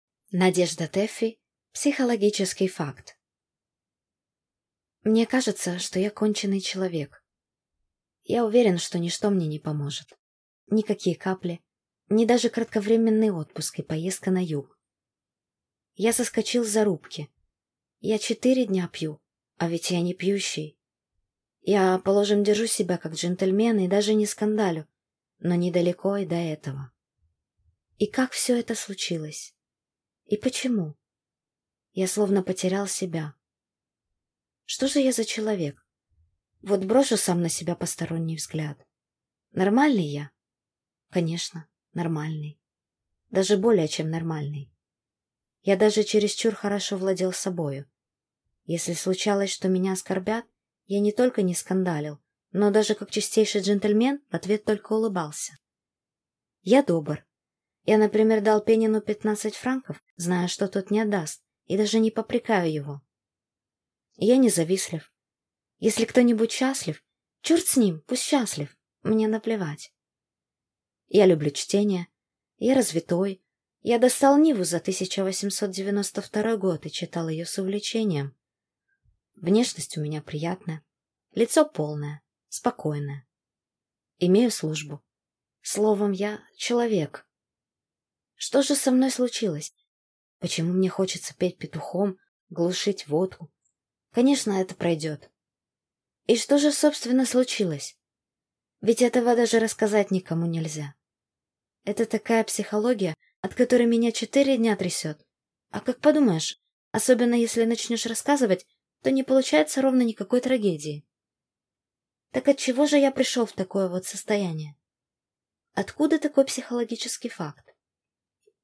Аудиокнига Психологический факт | Библиотека аудиокниг